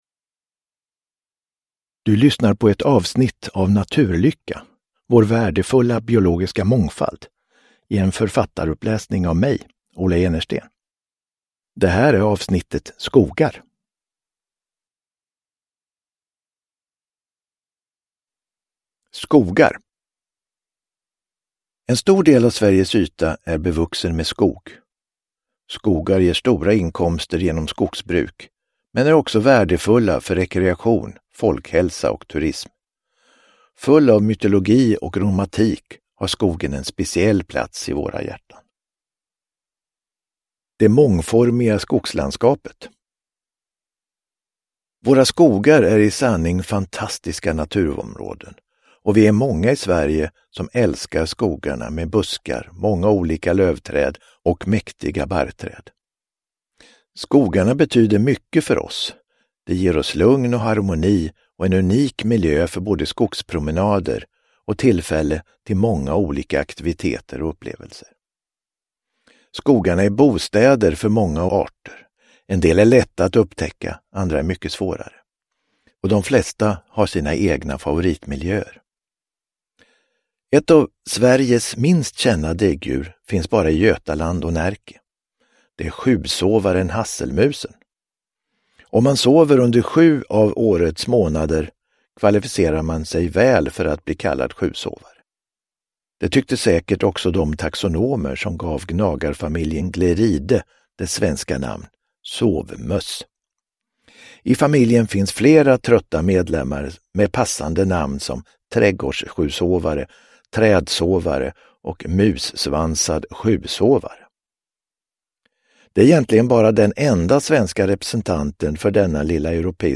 Naturlycka - Skogar – Ljudbok – Laddas ner